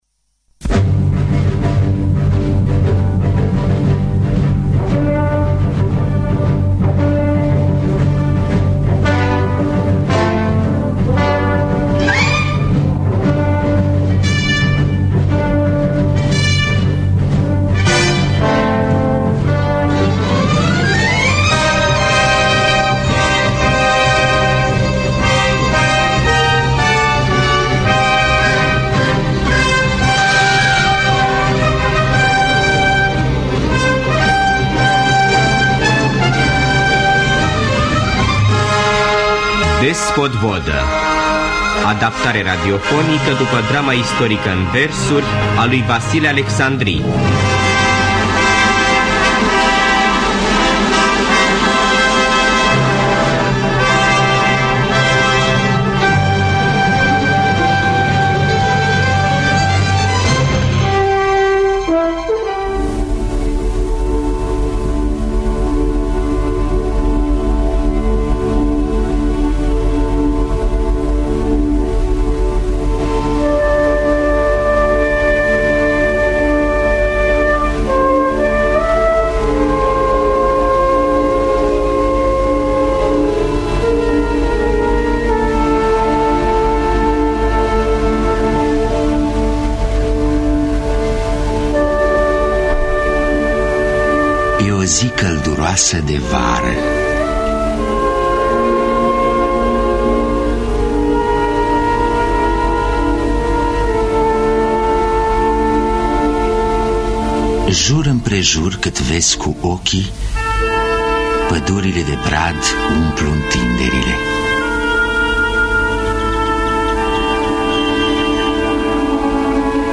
Despot Vodă de Vasile Alecsandri – Teatru Radiofonic Online